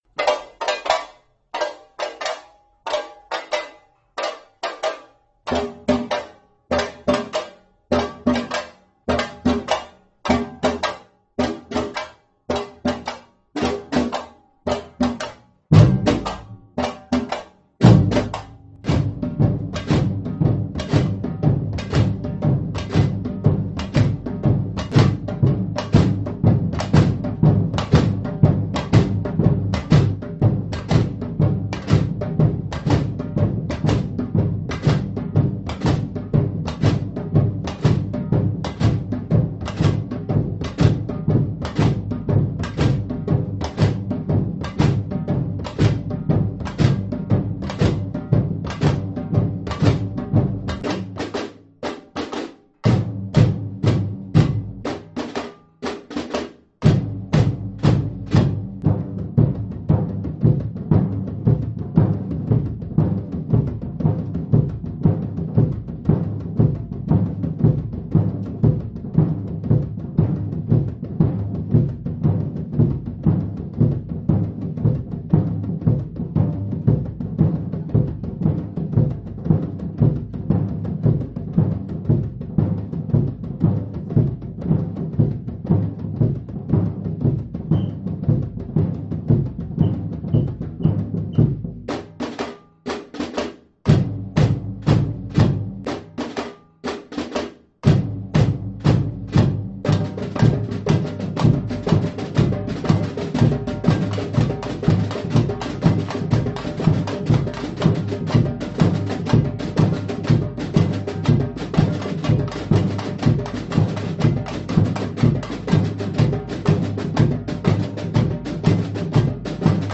Audio archive of the piece played in rehearsal